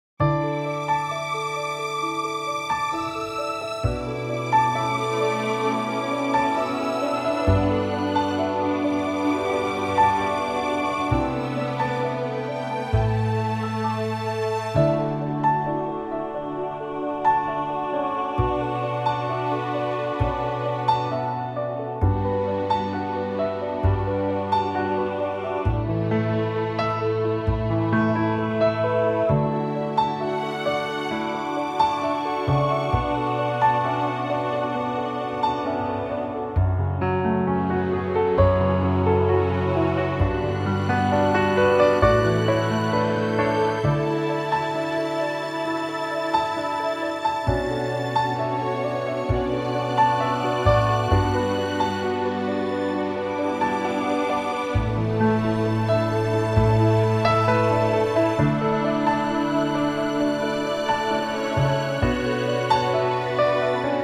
key - D - vocal range - F# to A (main theme range only)